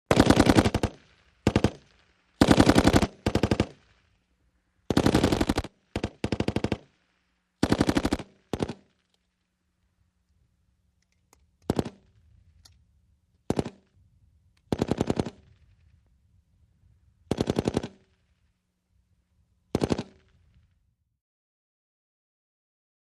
BritMachGunFire PE707001
WEAPONS - MACHINE GUNS HEAVY BRITISH GIMPY: EXT: Two guns firing multiple bursts, quick echos.